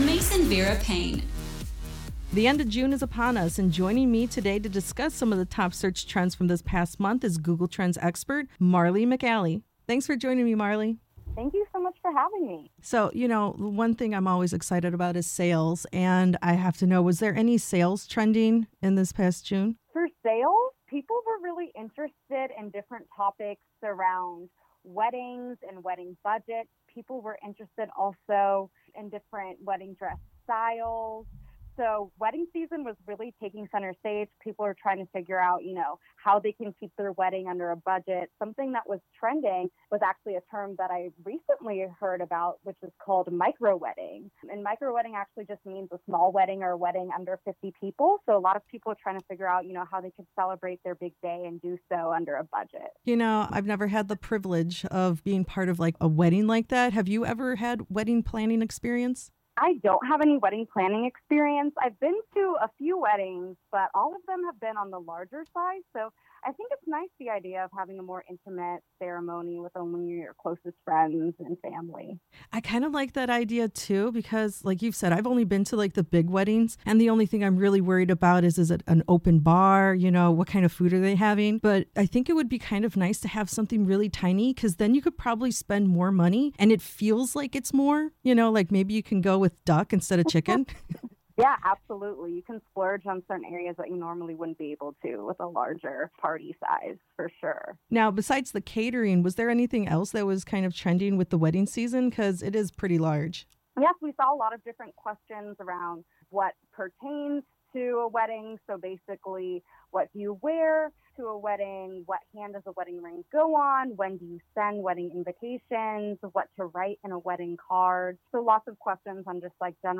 Google Top Searched June Trends Transcription